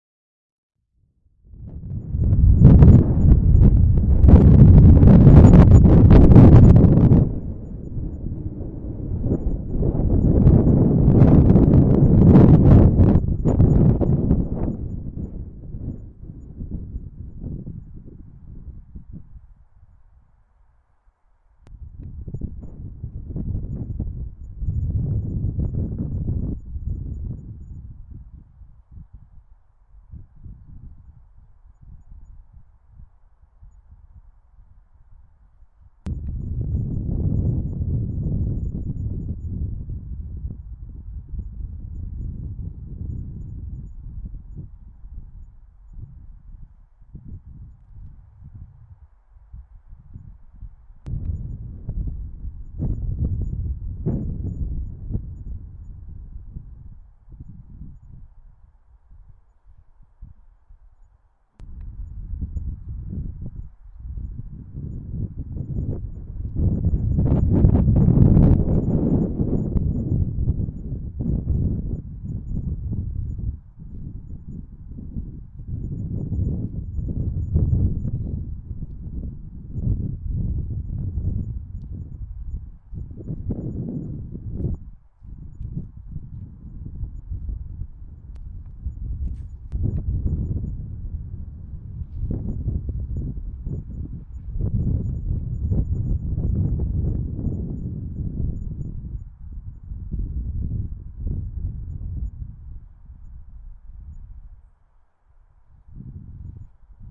风 " 风暴
描述：在夏天刮风的日子。在录音期间，您可以听到远处的一些教堂钟声。 AudioTechnica 835ST麦克风进入Marantz PMD 661 MkII。
标签： 神池 微风 大风 大风 树木 churchbells 阵风 场 - 记录
声道立体声